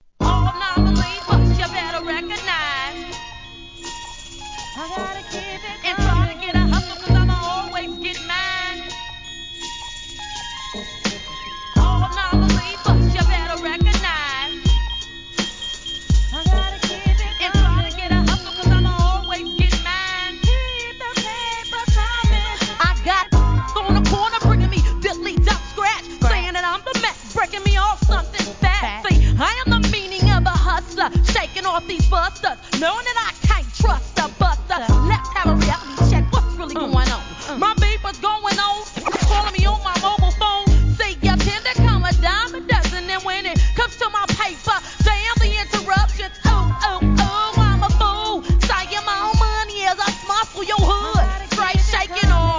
G-RAP/WEST COAST/SOUTH